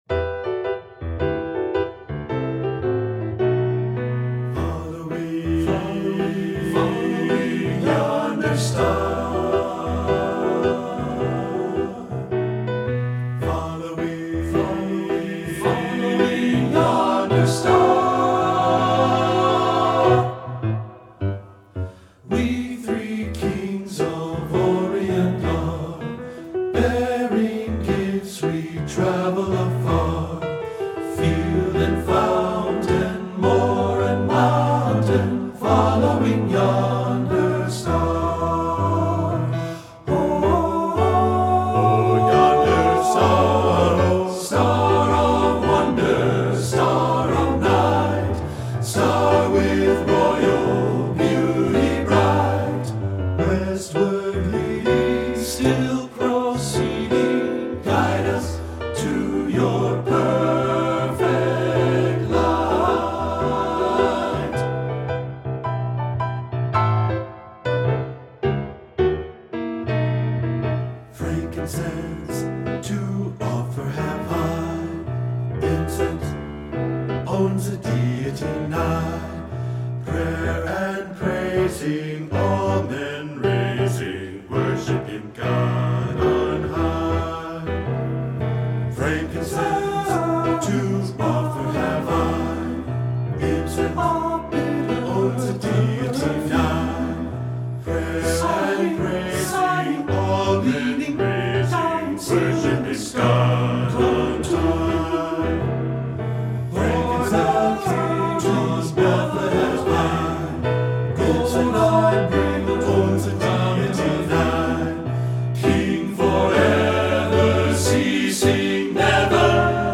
Voicing: TTB/TBB and Piano